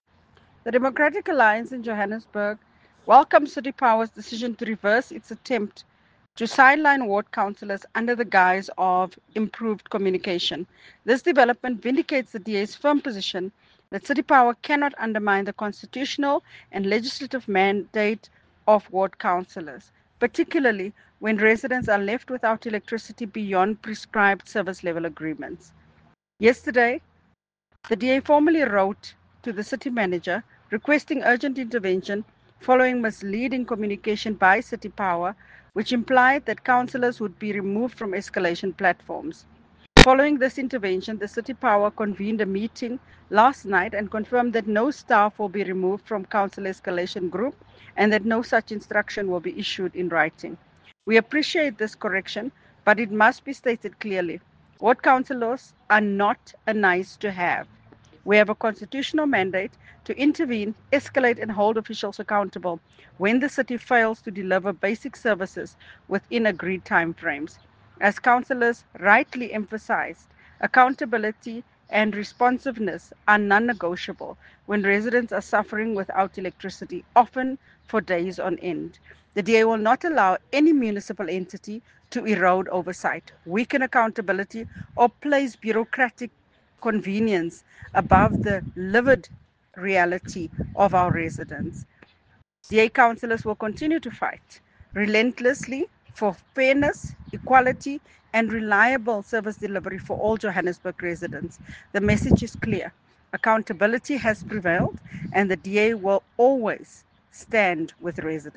English soundbite by Cllr Belinda Kayser-Echeozonjoku